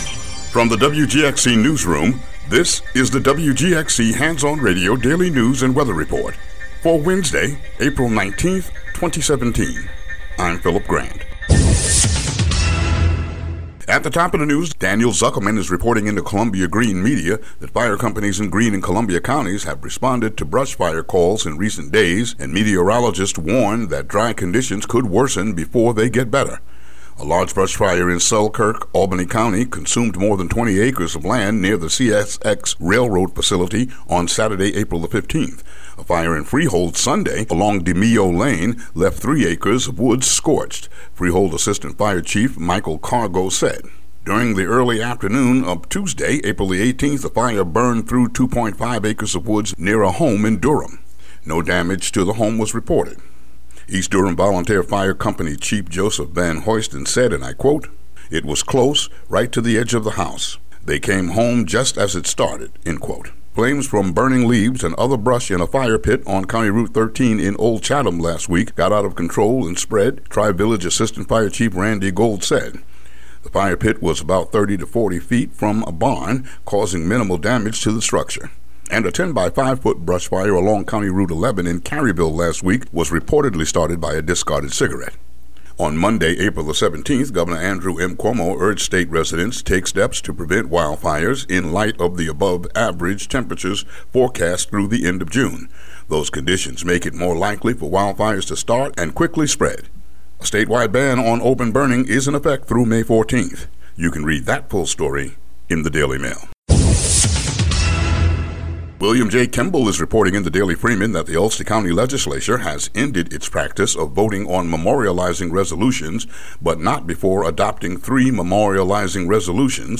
WGXC daily headlines for Apr. 19, 2017.